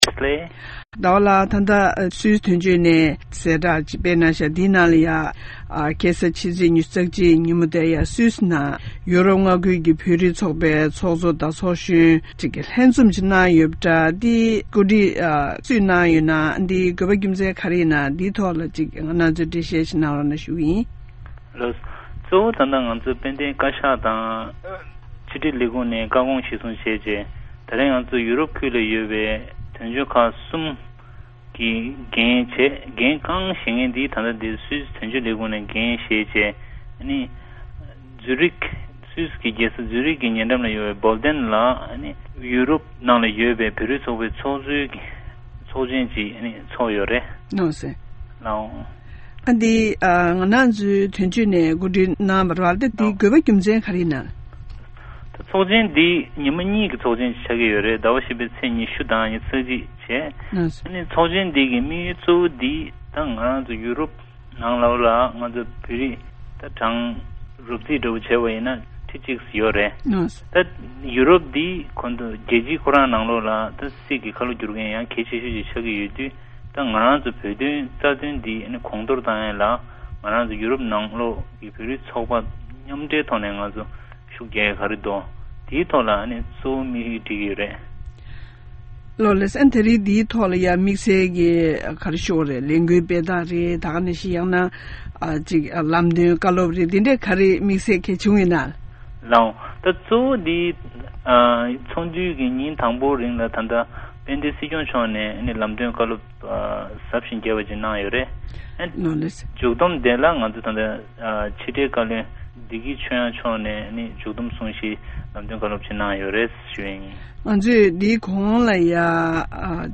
བཀའ་བློན་བདེ་སྐྱིད་ཆོས་དབྱངས་ལགས་ཀྱིས་གསུང་བཤད་གནང་བ།